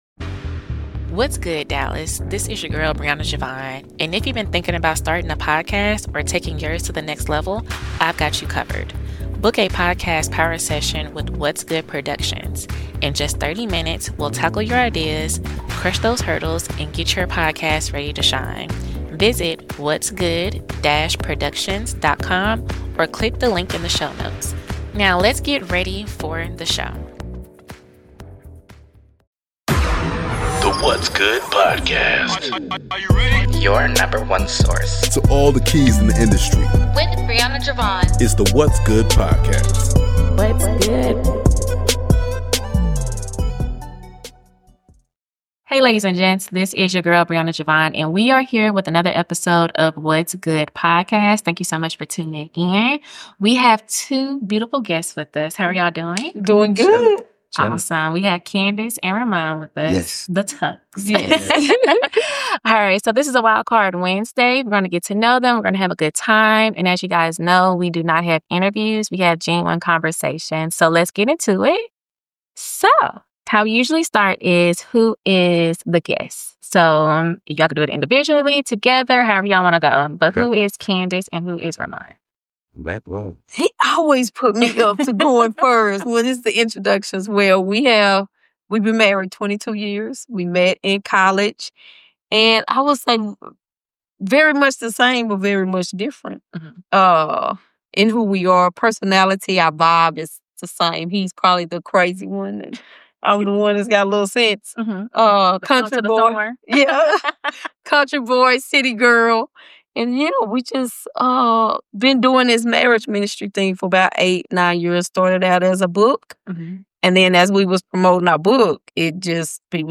From college days to counseling and building a marriage ministry, this heartfelt conversation offers practical wisdom, laughter, and healing for anyone navigating love.